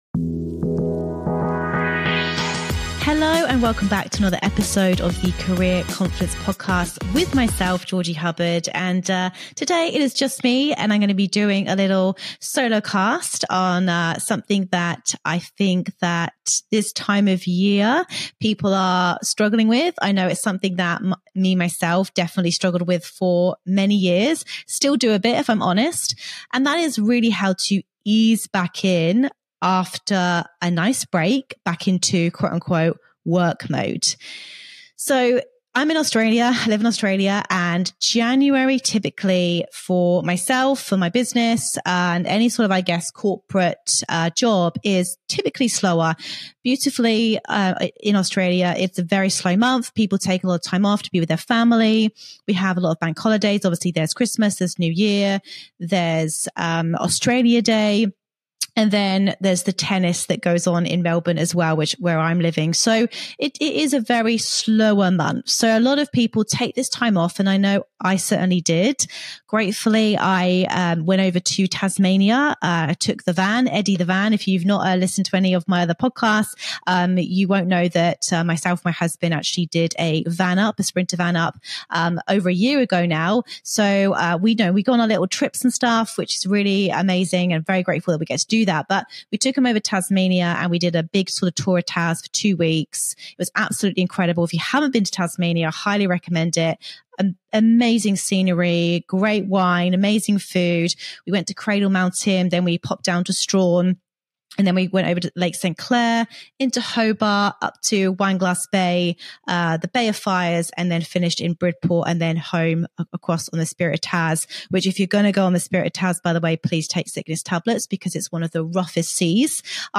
In today's solo episode